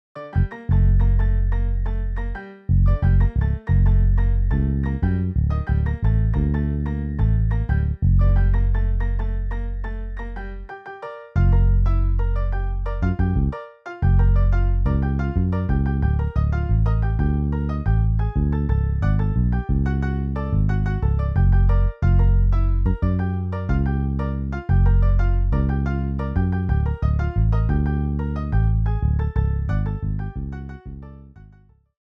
Saxo Alto, 2X Trompetas, 2X Trombones, Piano, Bajo